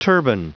Prononciation du mot turbine en anglais (fichier audio)
Prononciation du mot : turbine